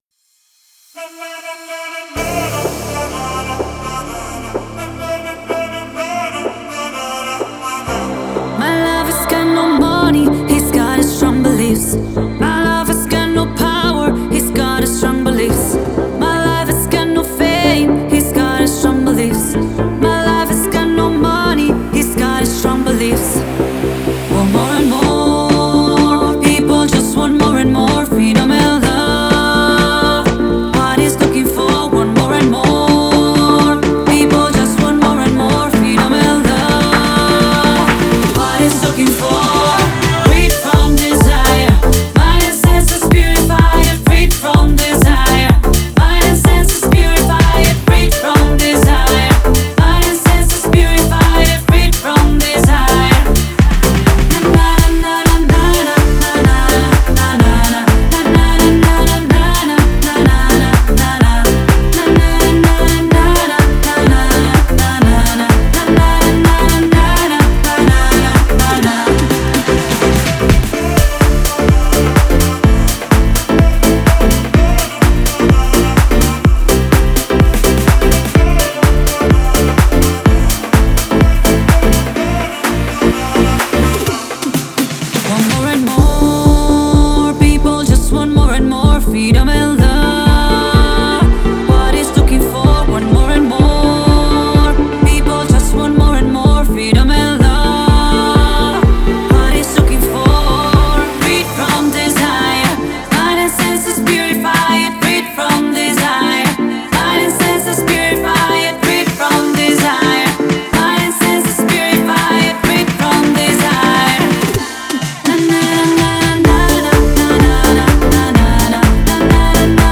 это энергичная и зажигательная песня в жанре хаус